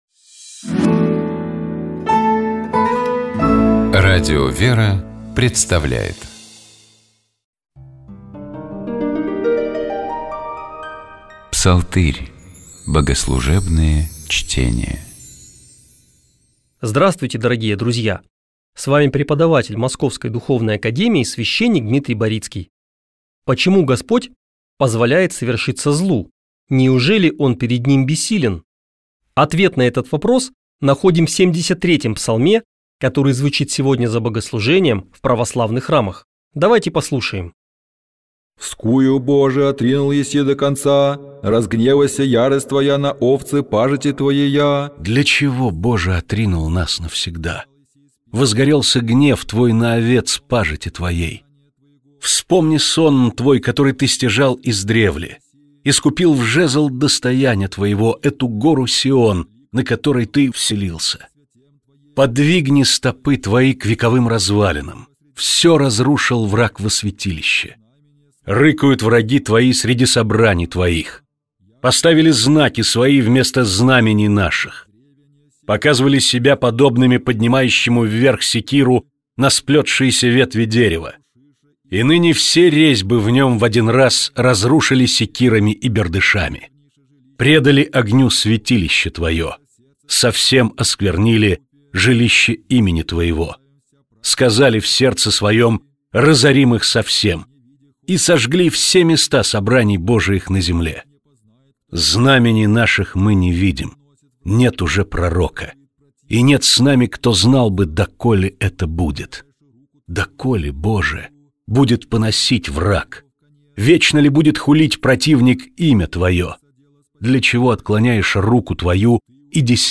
Псалом 73. Богослужебные чтения